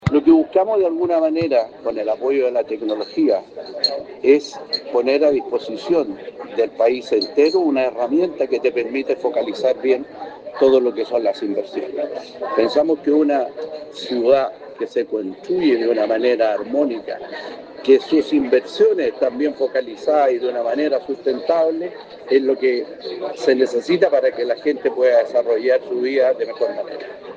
Estas cifras se dieron en el marco del seminario «Inversión en Ciudades», en Rancagua